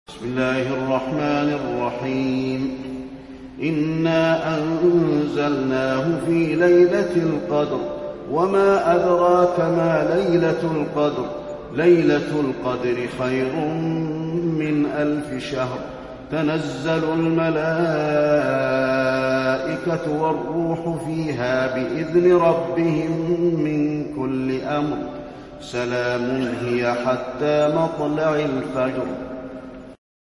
المكان: المسجد النبوي القدر